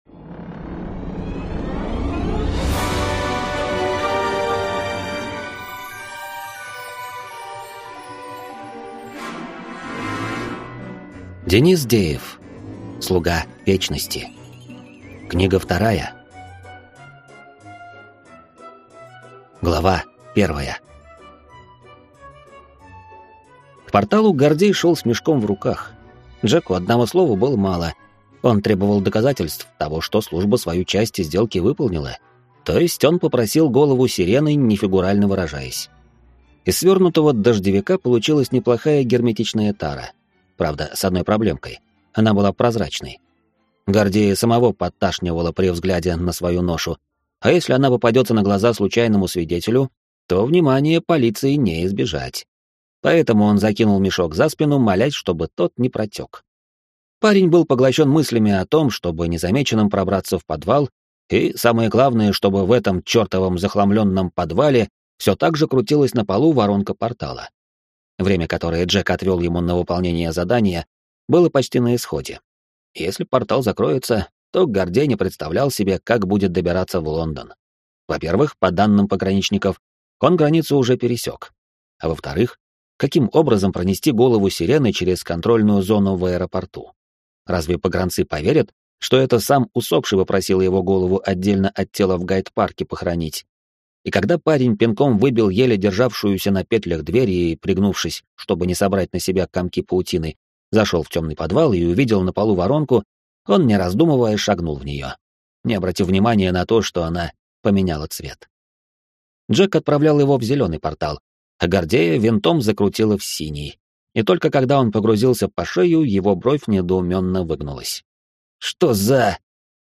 Аудиокнига Слуга вечности. Книга 2 | Библиотека аудиокниг